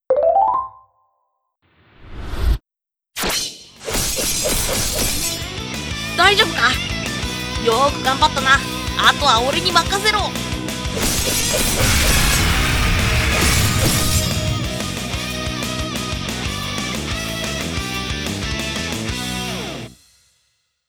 【Vtuber/七夕企画ボイス】 ピンチなリスナーの前に颯爽と駆け付ける